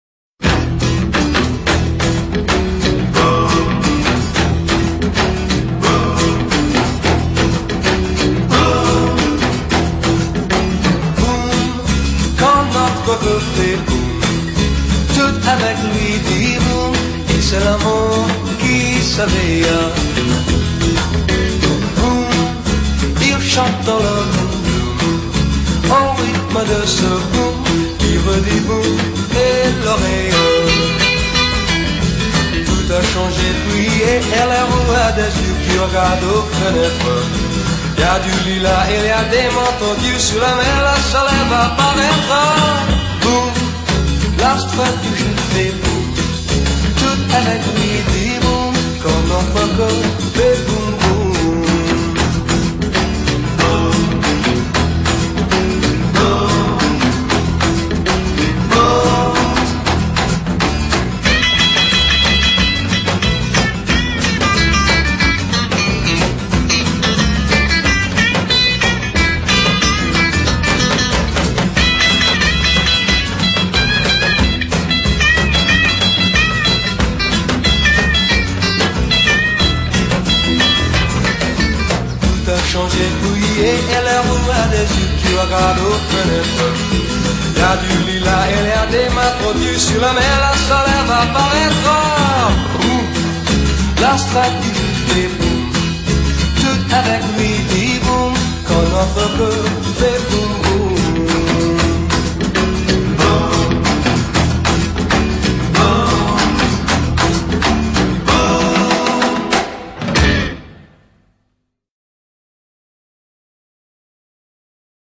qui revêtira à l’occasion une tenue plutôt rock’n’roll.